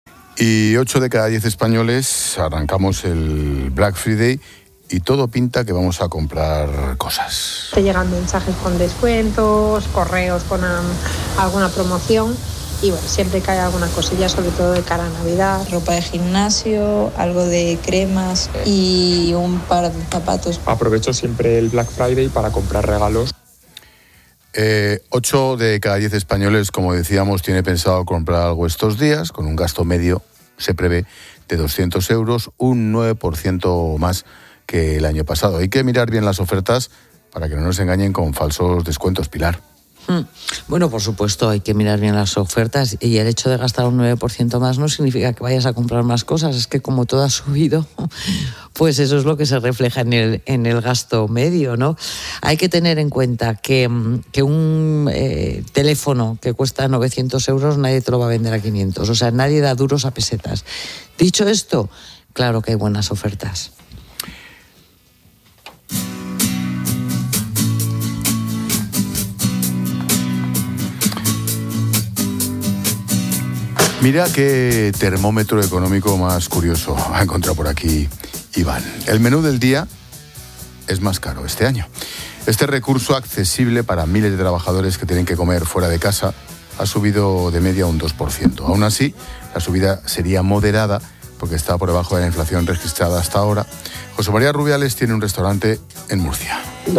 Expósito analiza con la experta económica y directora de Mediodía COPE, Pilar García de la Granja, las previsiones de gasto para el Black Friday